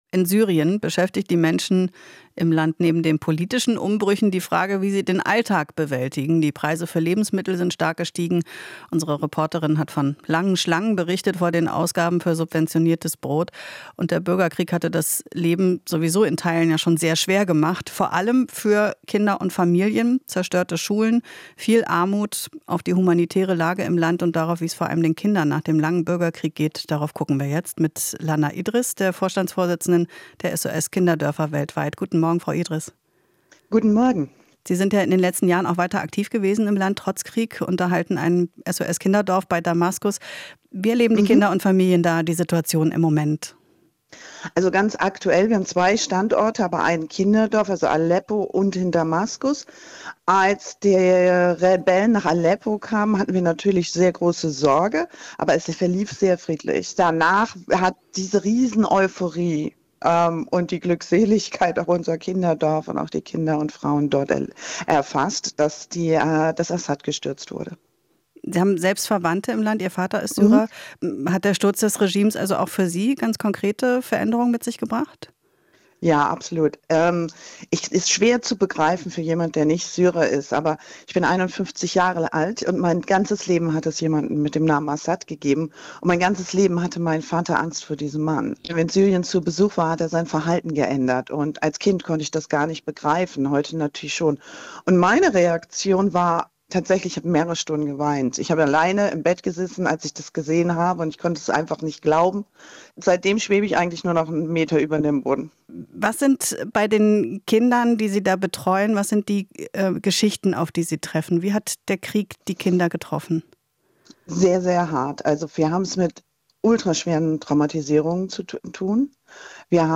Interview - SOS-Kinderdörfer: "Brauchen humanitäre Hilfe in Syrien"